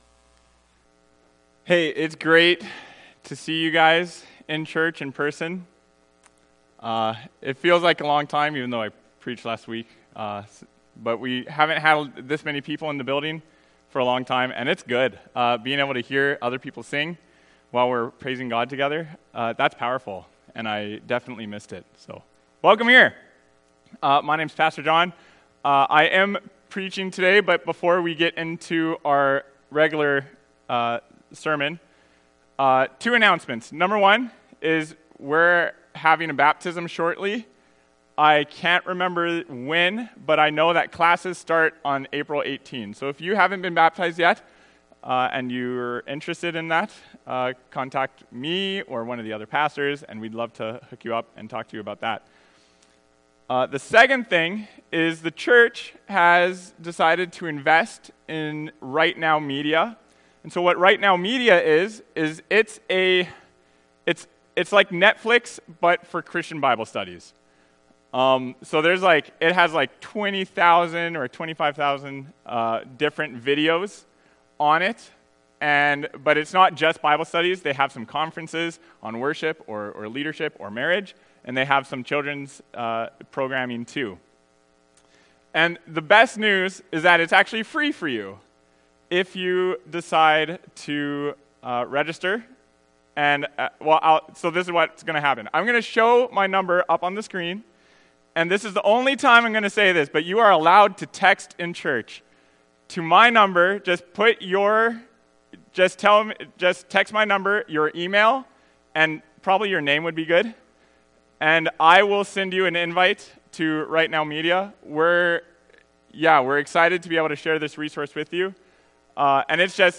Acts 2:14-24 Service Type: Sunday Morning Bible Text